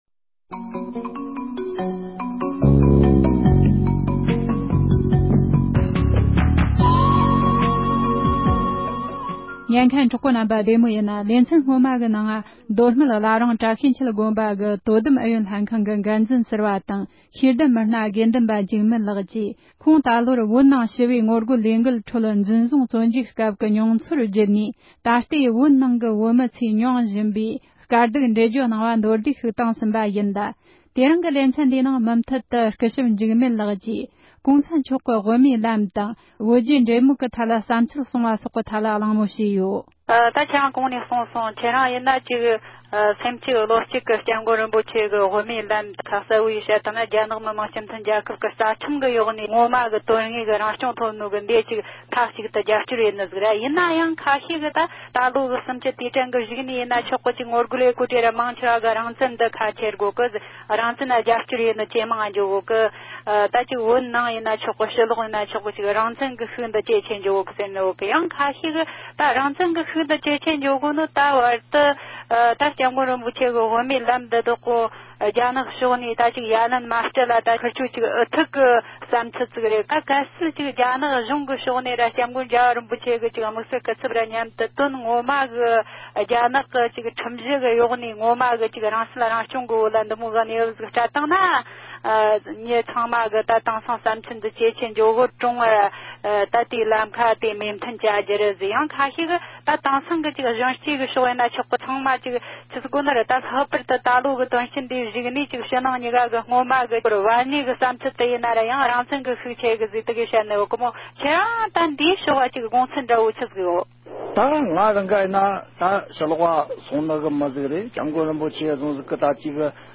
རྒྱ་གཞུང་གིས་འཛིན་བཟུང་བྱས་མྱོང་བའི་དགེ་འདུན་པ་ཞིག་གིས་༸གོང་ས་མཆོག་གི་དབུ་མའི་ལམ་དང་བོད་རྒྱའི་འབྲེལ་མོལ་སྐོར་གསུངས་བ།